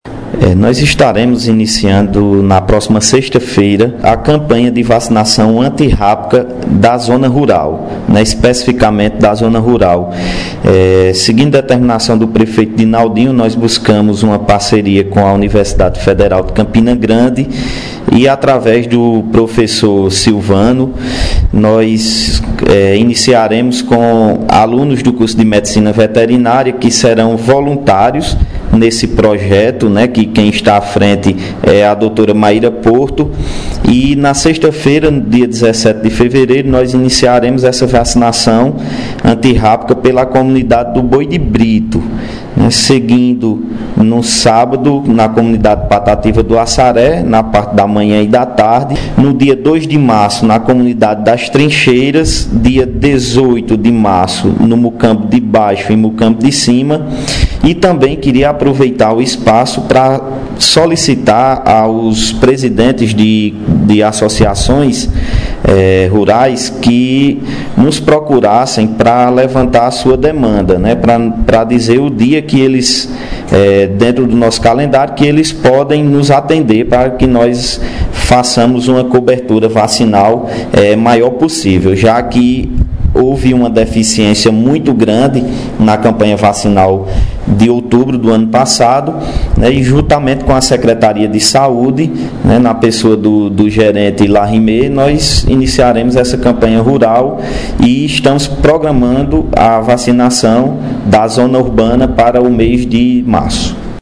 Fala do secretário de Agricultura, João Paulo de Lacerda sobre a Campanha de Vacinação Antirrábica –